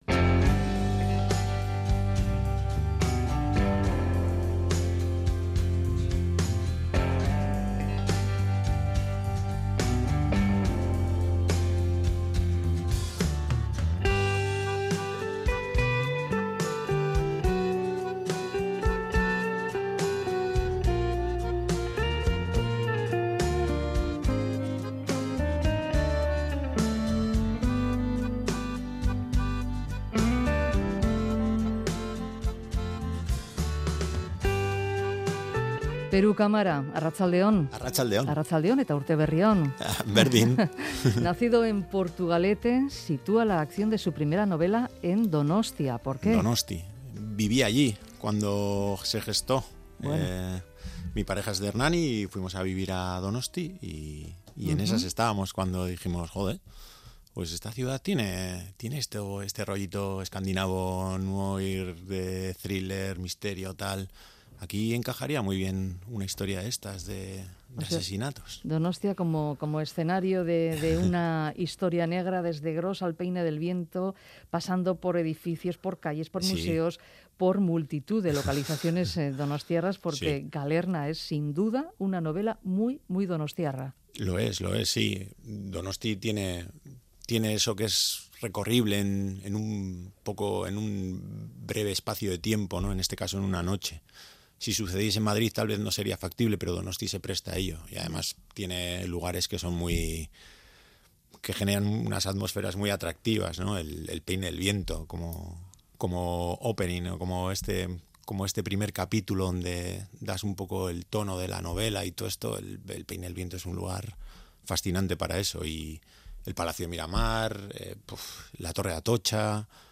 Radio Euskadi ENTREVISTAS